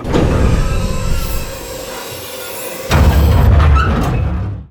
bayopen.wav